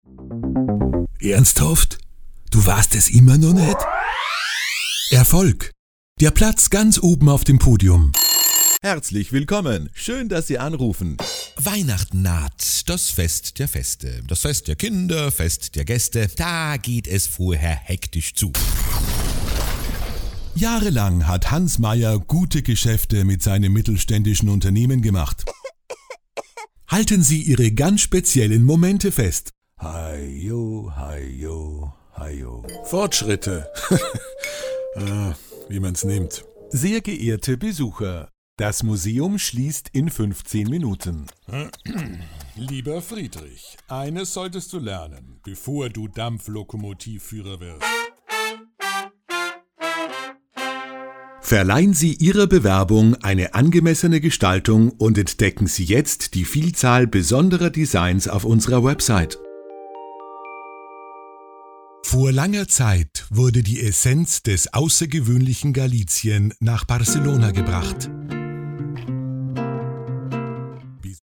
SPRACHAUFNAHMEN
Erweitern Sie Ihr 3D-Video mit einer professionellen sprachlichen Vertonung, die perfekt auf Ihre Bedürfnisse zugeschnitten ist.